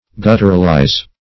Search Result for " gutturalize" : The Collaborative International Dictionary of English v.0.48: Gutturalize \Gut"tur*al*ize\, v. t. To speak gutturally; to give a guttural sound to.